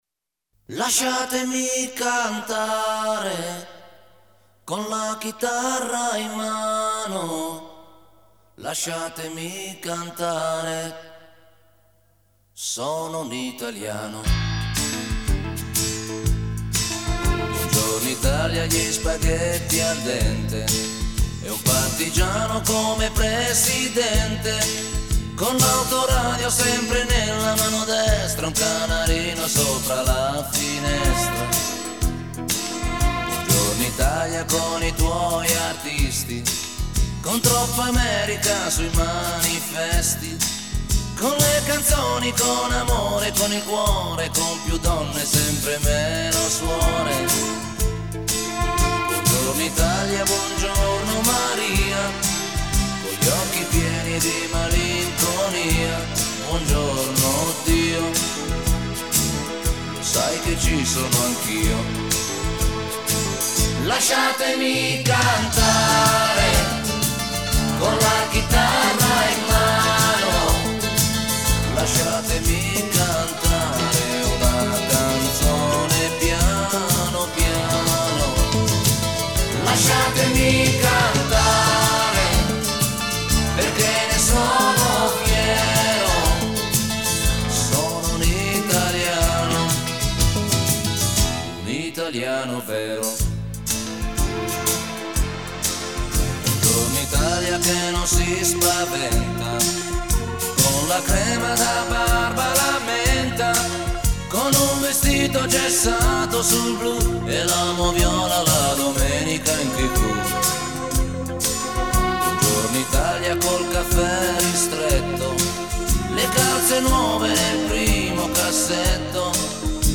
CD version/quality